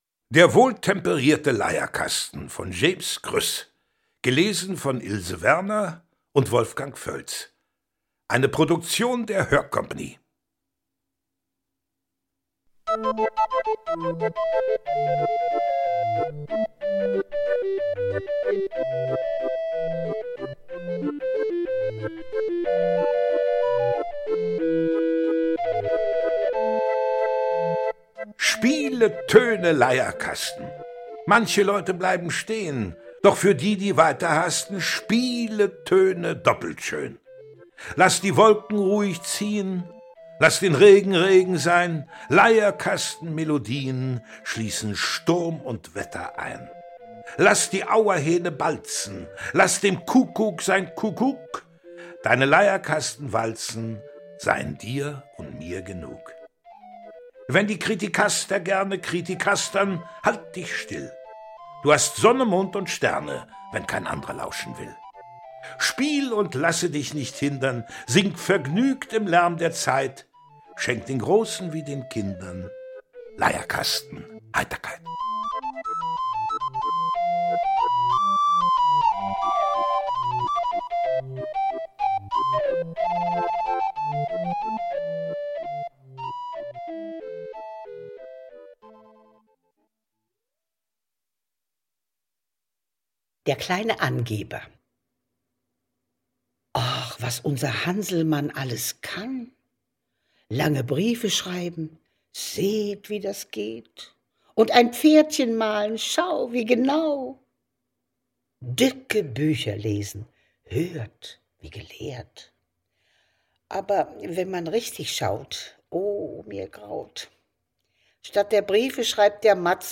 Lesung mit Musik mit Wolfgang Völz und Ilse Werner (1 CD)
Wolfgang Völz, Ilse Werner (Sprecher)